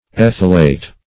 ethylate - definition of ethylate - synonyms, pronunciation, spelling from Free Dictionary
Ethylate \Eth"yl*ate\ ([e^]th"[i^]l*[asl]t), n. [From Ethyl.]